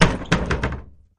Cellar Door Open and Bounce